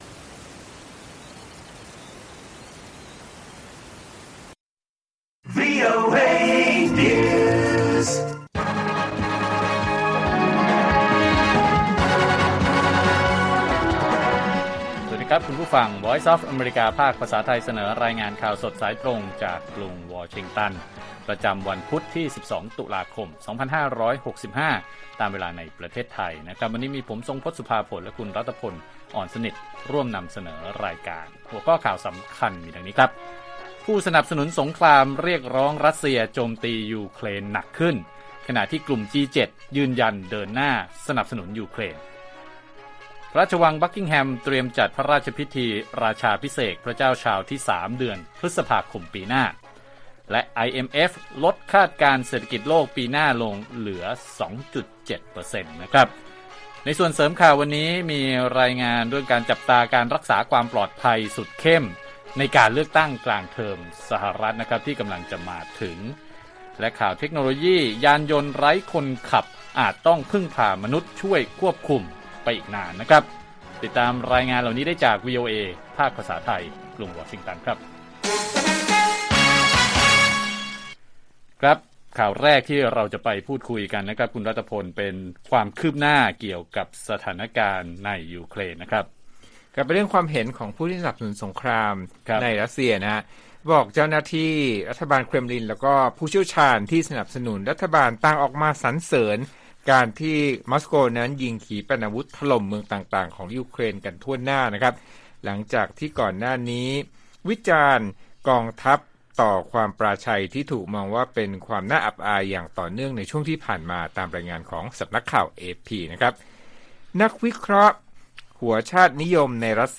ข่าวสดสายตรงจากวีโอเอ ไทย พุธ 12 ตุลาคม 2565